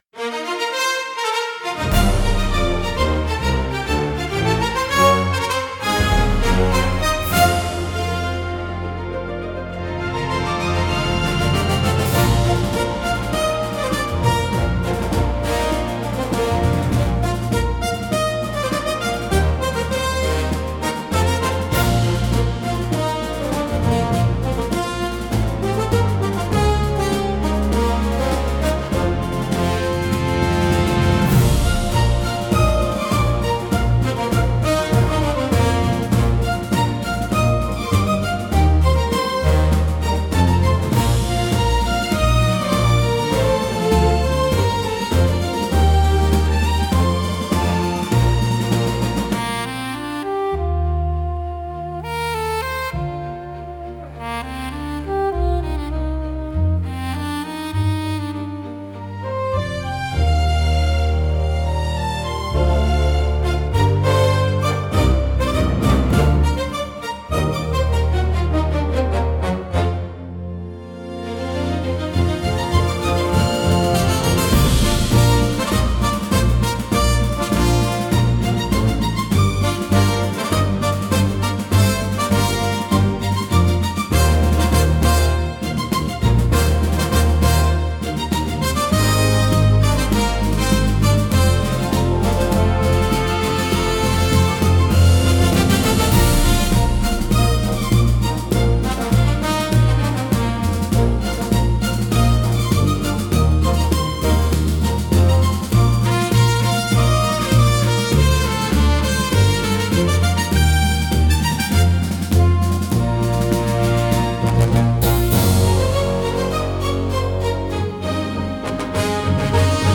高揚感と喜びを強調し、場の雰囲気を盛り上げる役割を果たします。華やかで勢いのあるジャンルです。